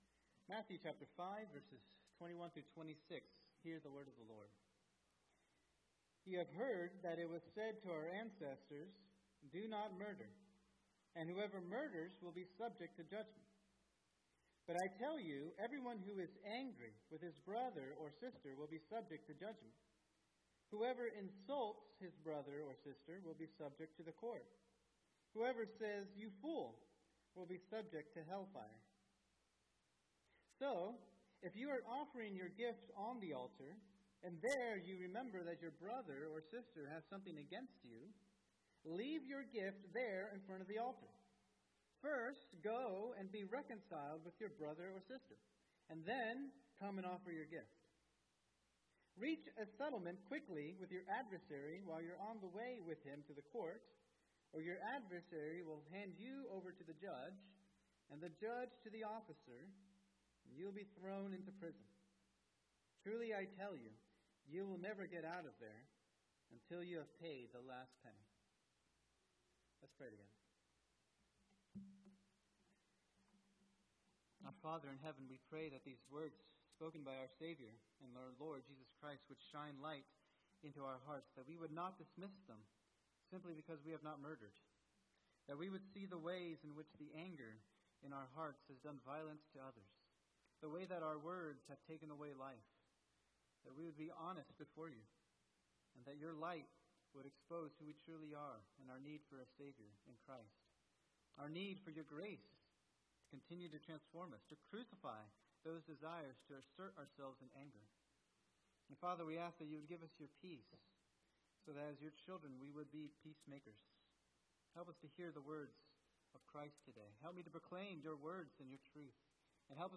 Sermon
2024 at First Baptist Church in Delphi, Indiana.